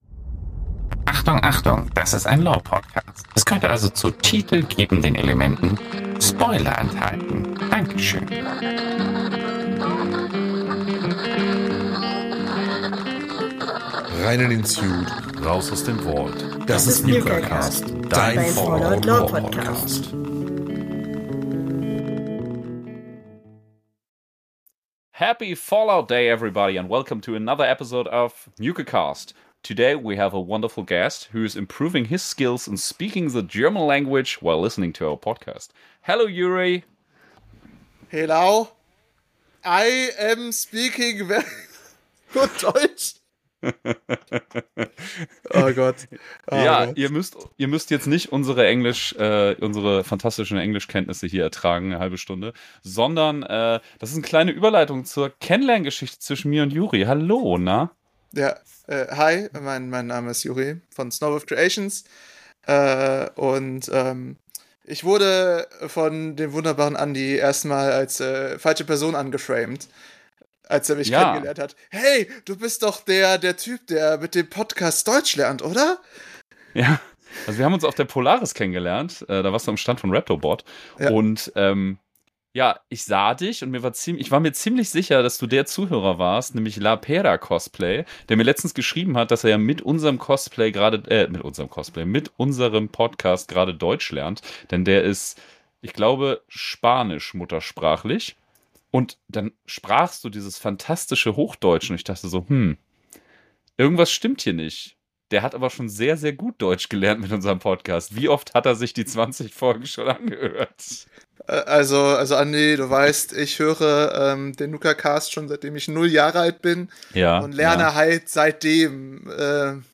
Wir quatschen über Fallout, Cosplay, Raptobot und wünschen euch mit diesem gut gelaunten Talk einen wundervollen Fallout-Day!